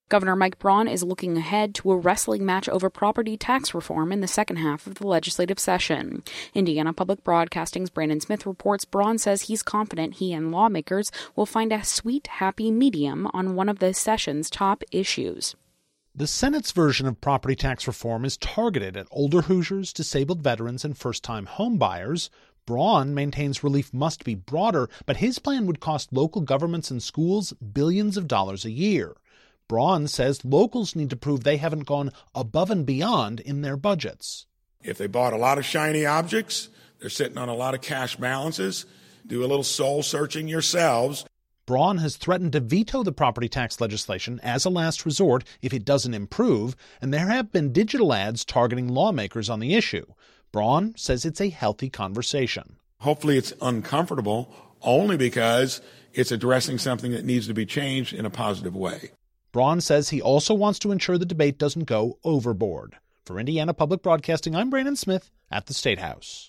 Listen in Popup Download MP3 Comment Gov. Mike Braun said during a press conference on Feb. 25, 2025 that he's confident he and lawmakers will find a "sweet, happy medium" on property tax reform.